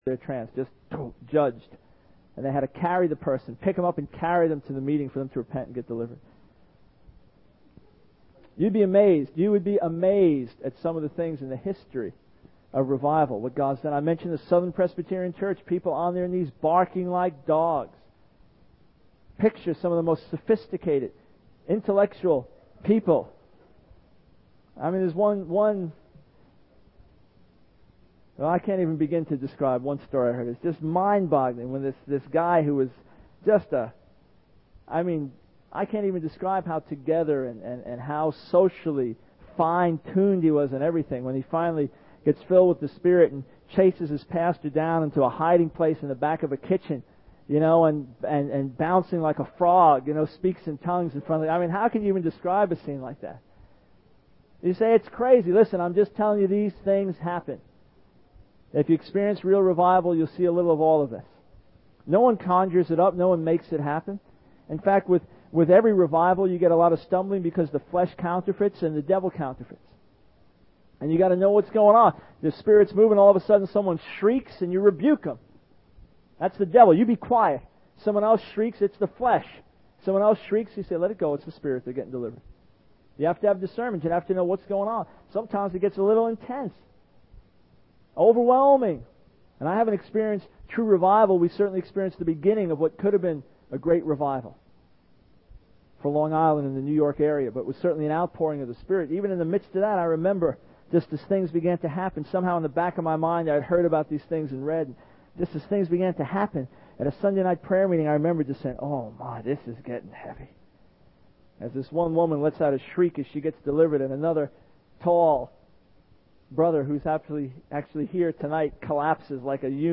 In this sermon, the speaker shares his experiences of witnessing supernatural manifestations during a revival.